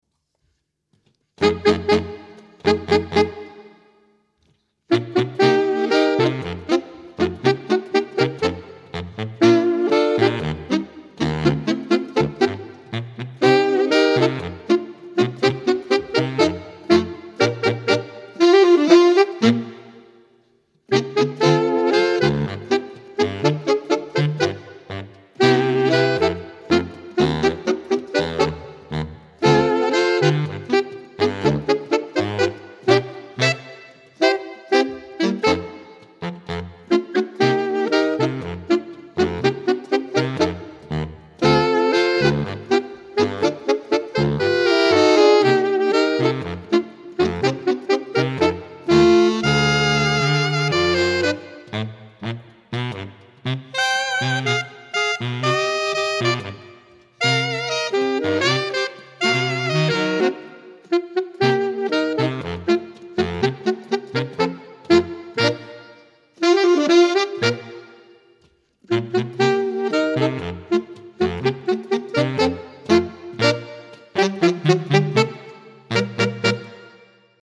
Ranges: Alto 1: F3. Tenor: C3. Baritone: B1
More Saxophone Quartet Music